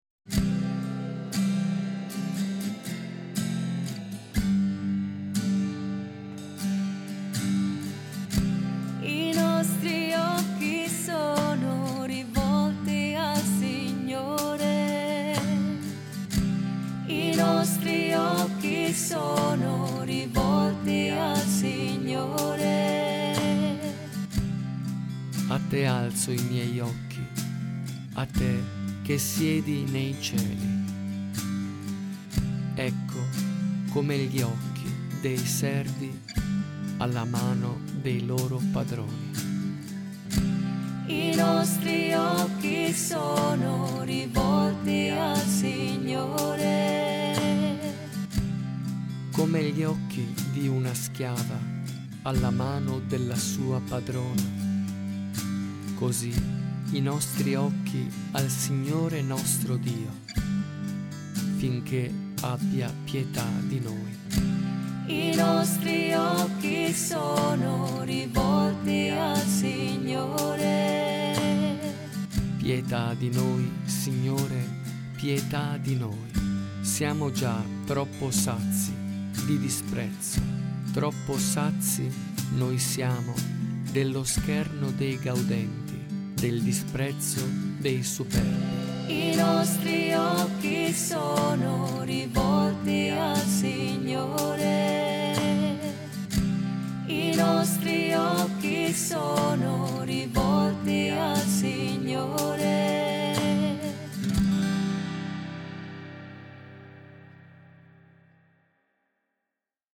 SALMO RESPONSORIALE
RITORNELLO CANTATO